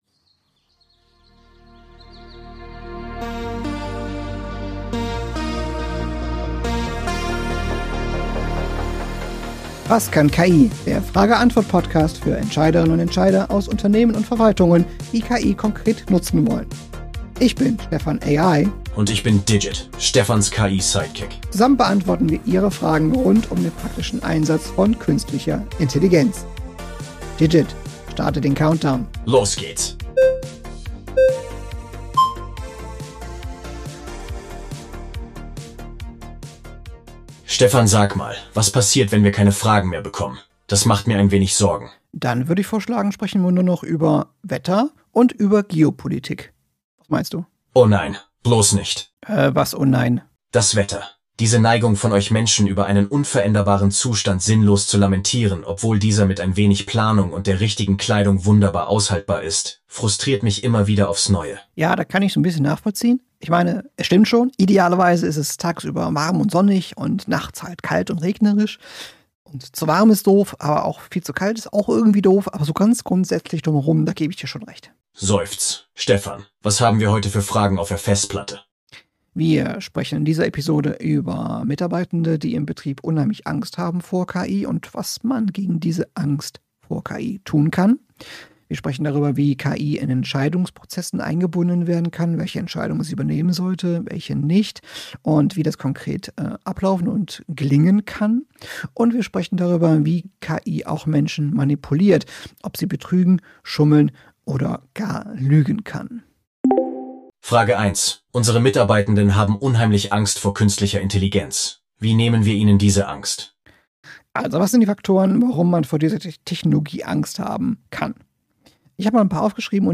Dieser Call-In-Podcast bietet die Plattform, um konkrete Fragen rund um künstliche Intelligenz (KI) zu stellen und wertvolle Einblicke in deren praktische Anwendung in Wirtschaft und öffentlicher Ver